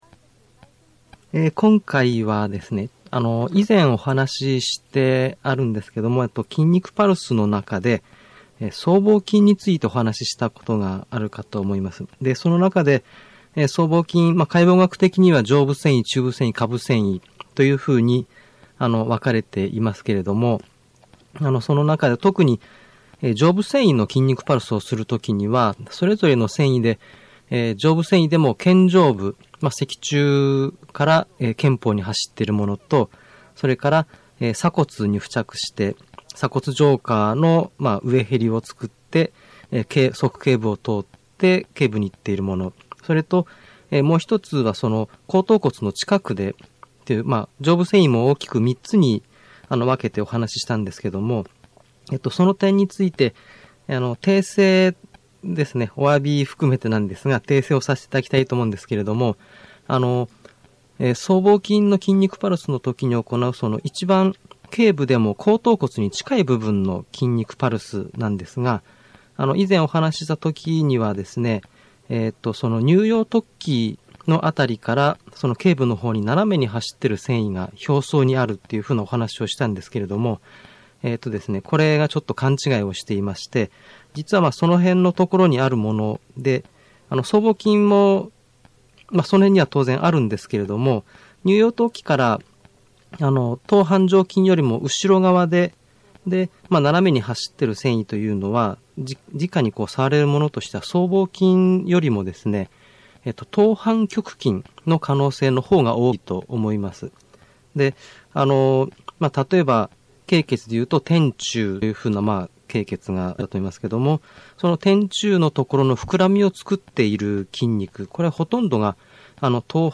今回は「筋肉パルス」について、僧帽筋上部線維の通電方法を含めて、 初心者の方に向けて、基本的なことをお話ししています。 社会福祉法人 日本点字図書館様作成の1999年５月のテープ雑誌 新医学より一部抜粋したものです。